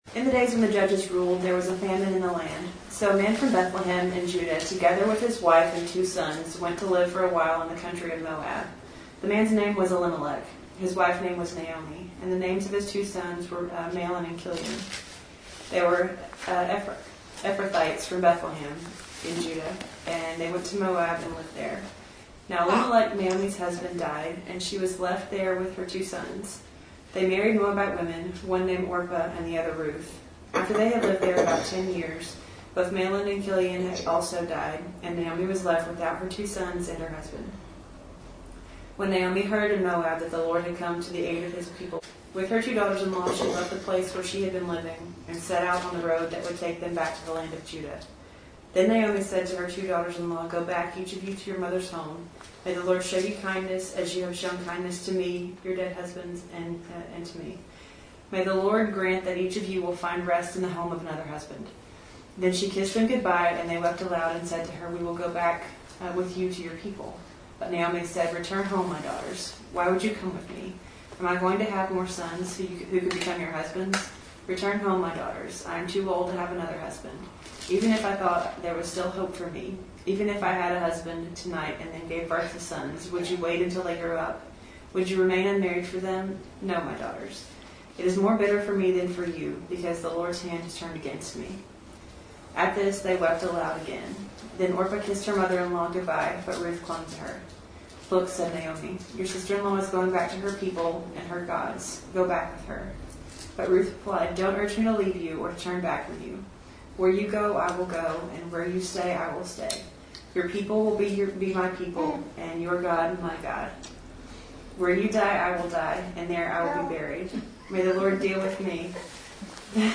Series: Ruth and the Hope of the World Passage: Ruth 1:1-22 Service Type: Sunday Morning